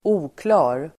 Uttal: [²'o:kla:r]